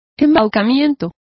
Complete with pronunciation of the translation of deception.